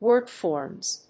wordforms.mp3